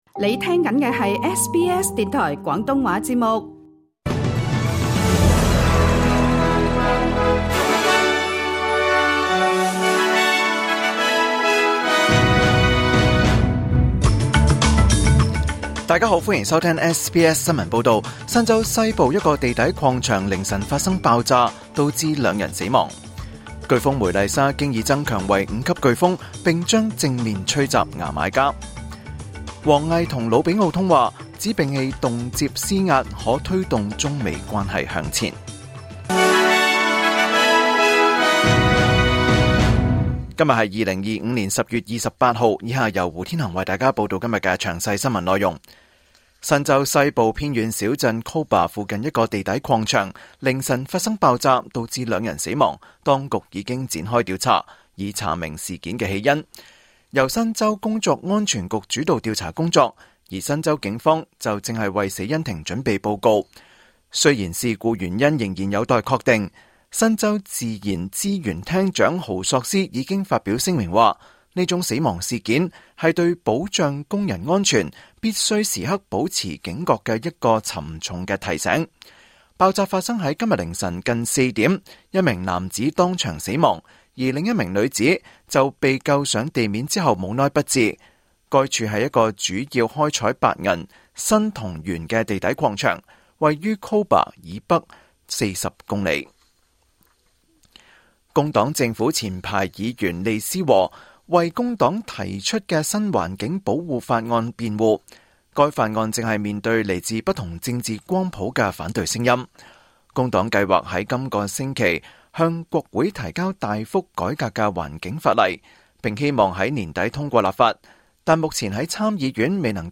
SBS廣東話新聞報道